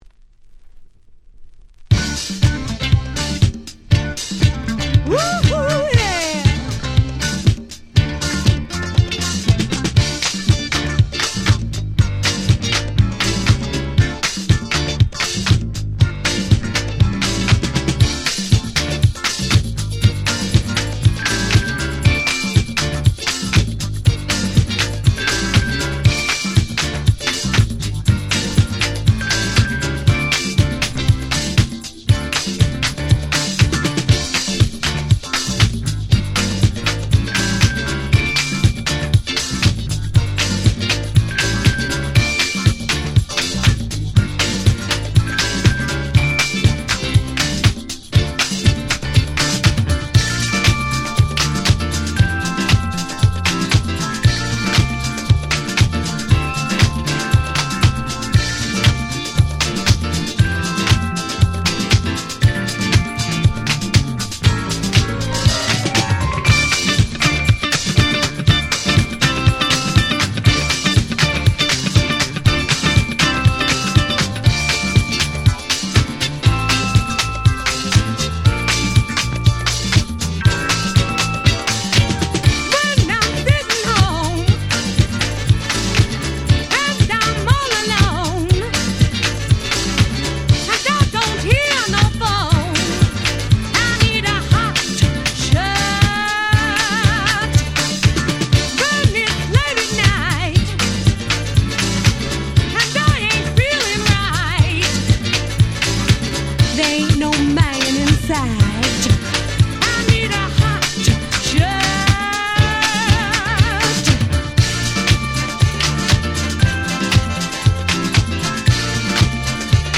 78' Disco / Dance Classics Super Classics !!
78年のディスコヒットの後発リミックス。